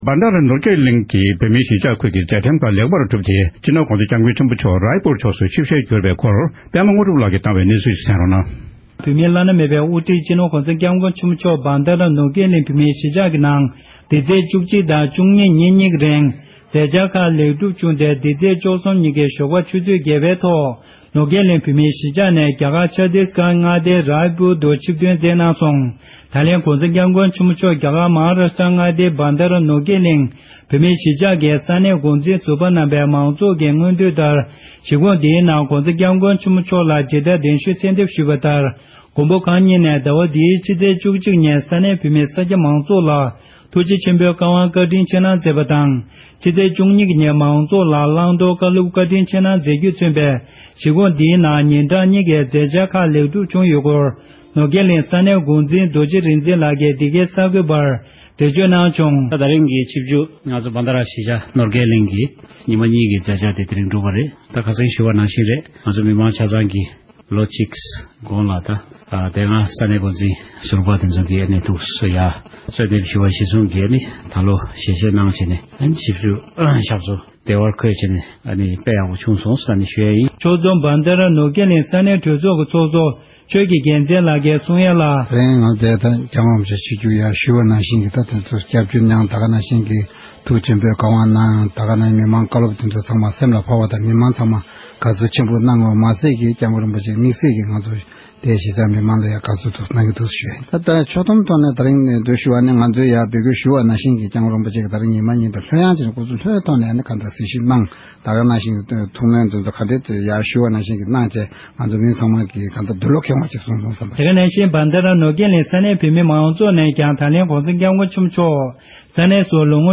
གཞིས་ཆགས་ནང་གནས་སྡོད་འབྲེལ་ཡོད་མི་སྣར་གནས་འདྲི་ཞུས་ཏེ་ཕྱོགས་སྒྲིག་ཞུས་པར་གསན་རོགས༎